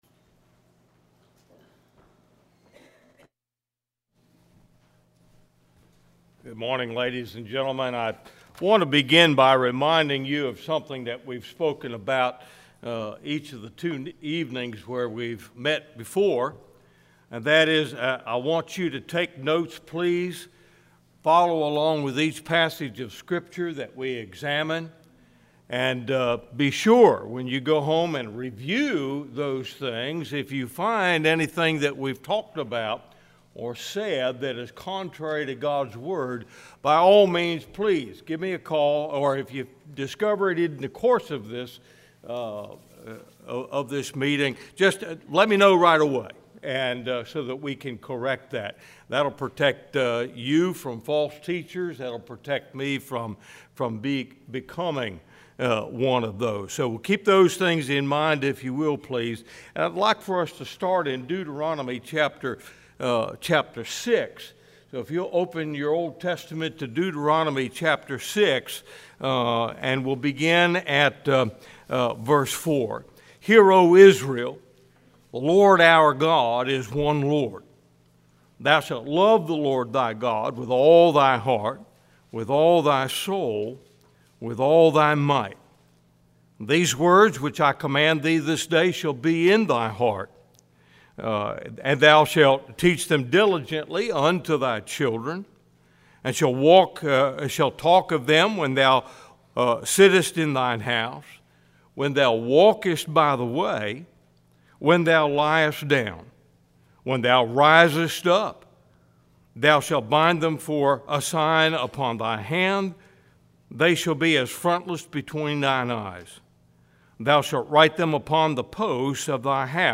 Sermons in Mp3 & PowerPoint